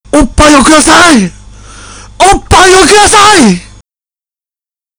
叫んでみました